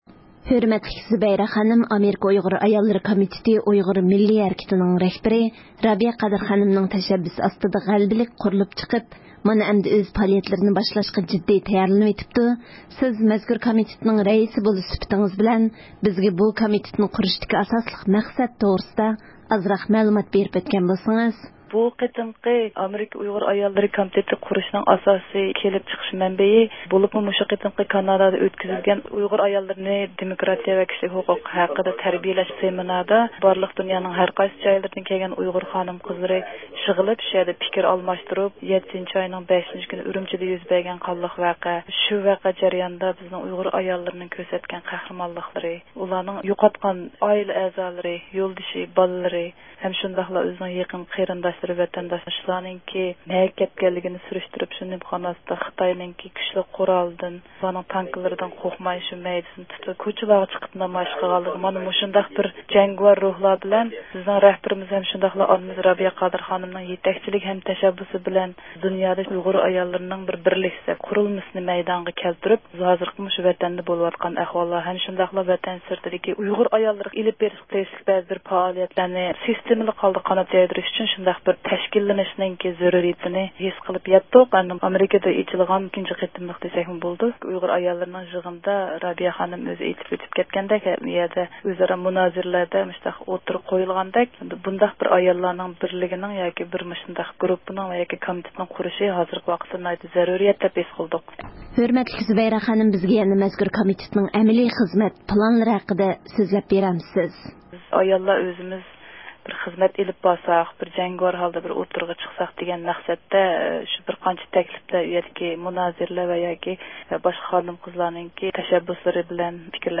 مۇخبىرىمىزنىڭ زىيارىتىنى قوبۇل قىلىپ